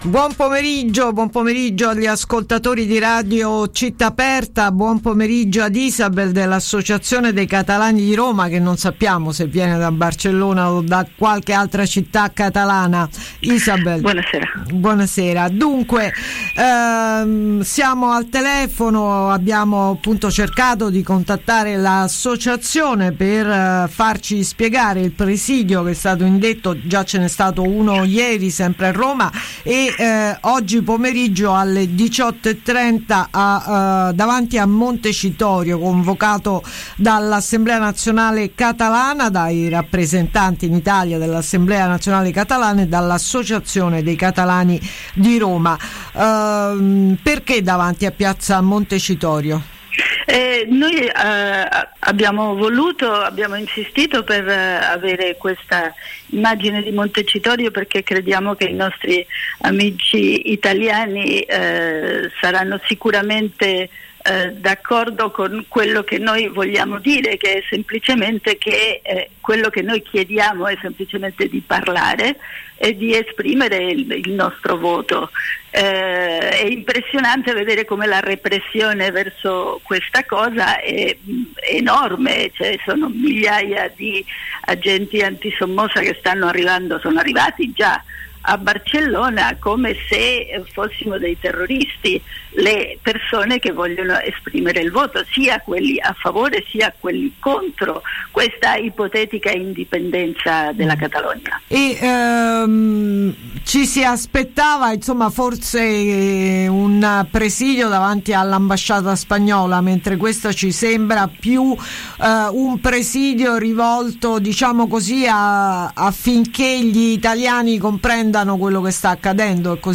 Catalogna – Intervista Associazione dei Catalani di Roma